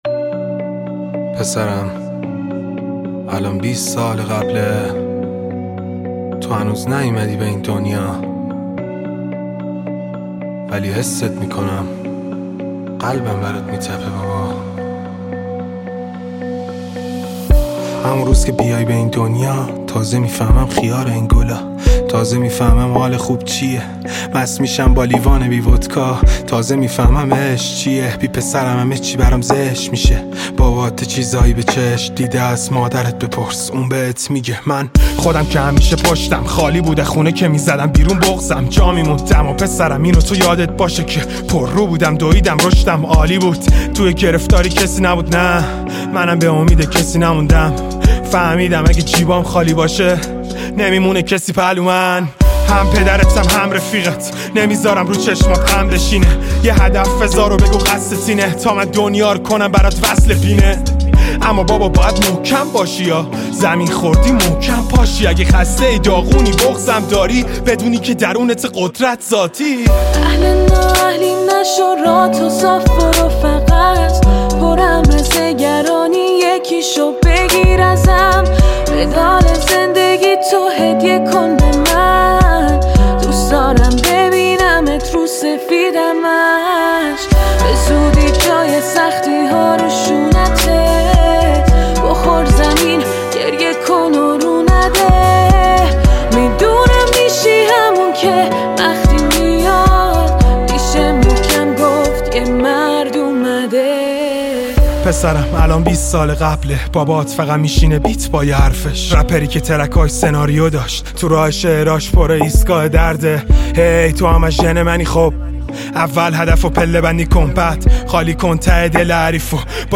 گنگ رپ